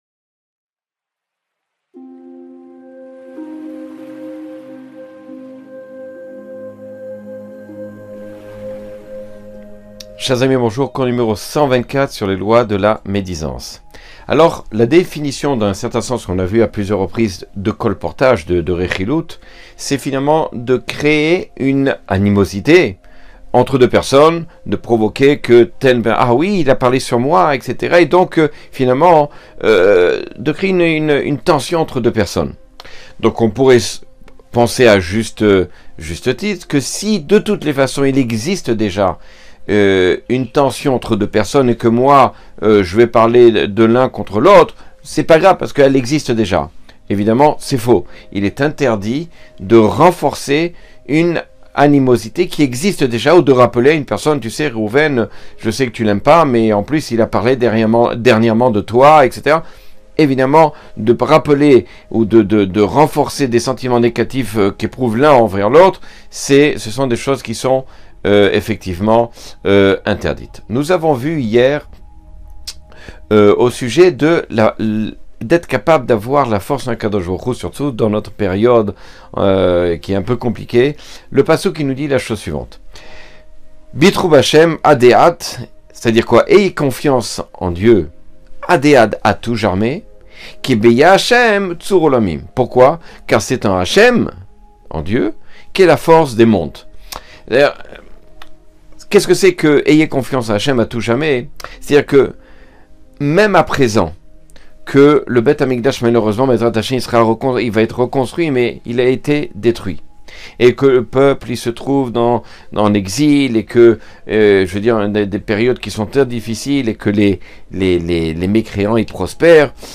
Cours 124 sur les lois du lashon hara.